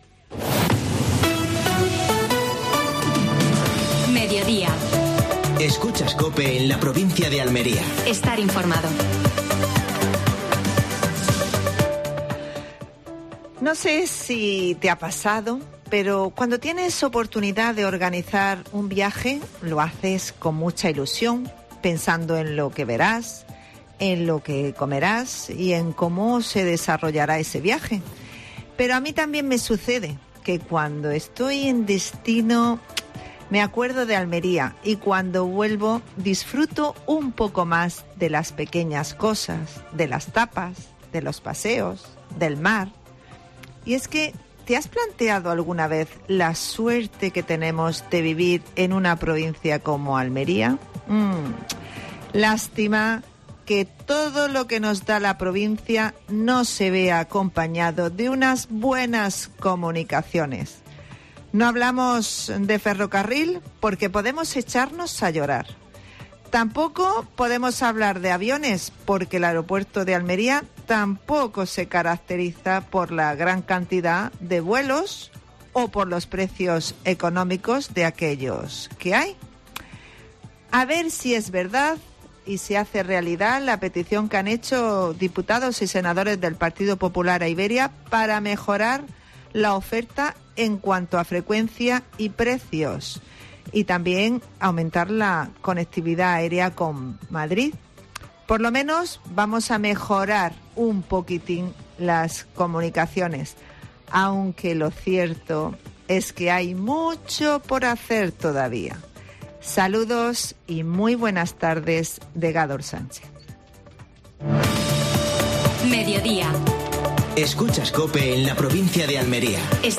AUDIO: Actualidad en Almería. Entrevista al Grupo Almenara. Última hora deportiva.